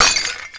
ceramic_impact3.wav